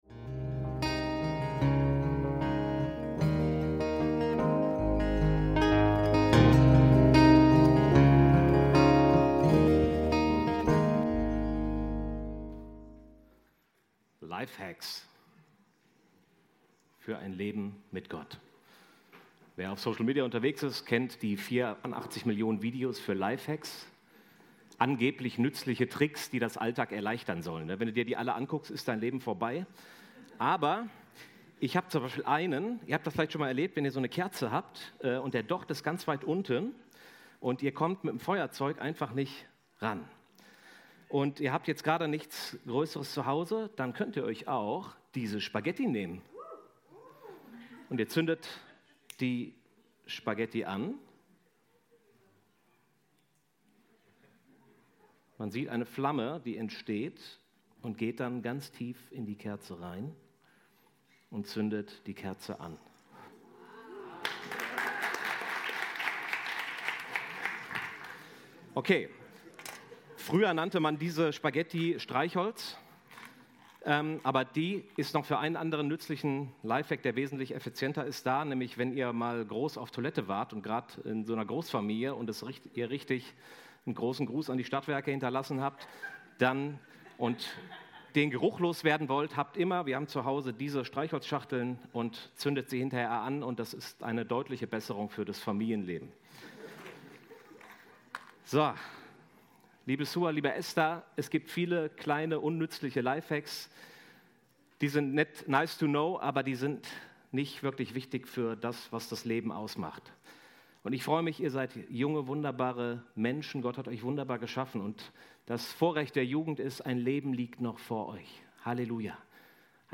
Lifehacks für ein Leben mit Gott – Predigt vom 06.07.2025 ~ FeG Bochum Predigt Podcast